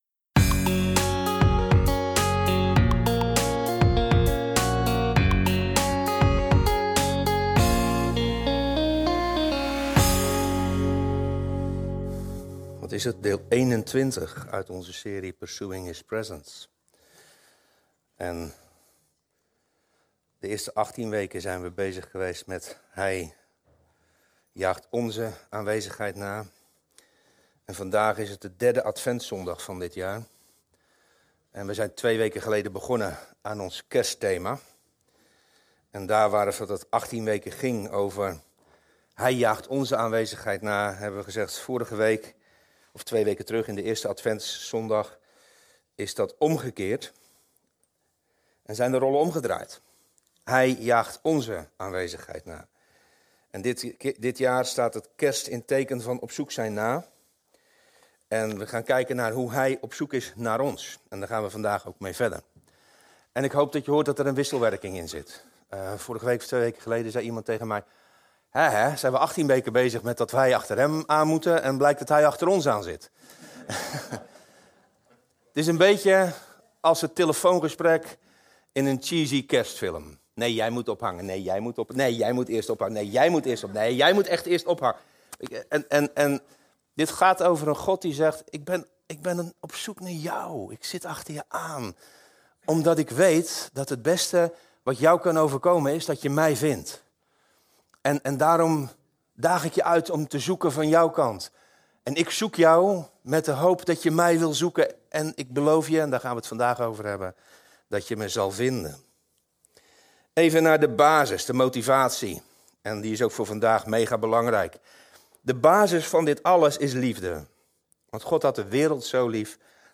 Dit kanaal bevat de preken zoals deze op zondagochtend in onze gemeente worden gehouden.